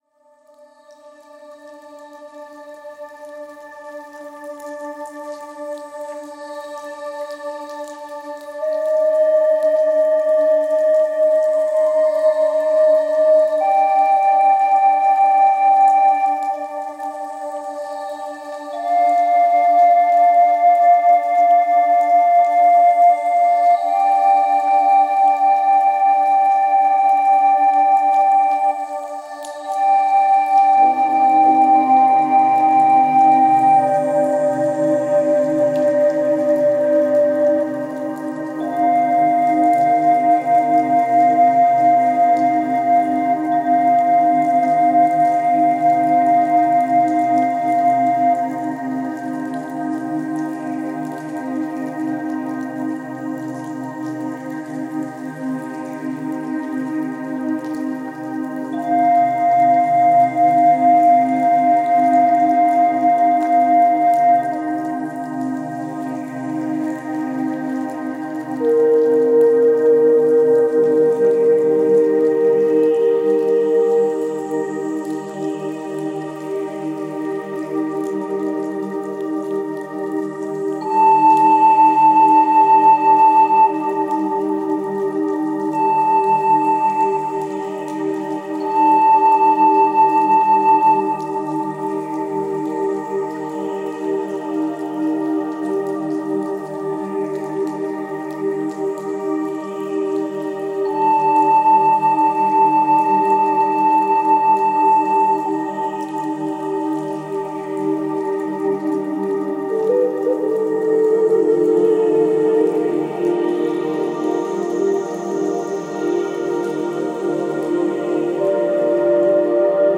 Музыка природы 0 456 1 1 Добавлено в плейлист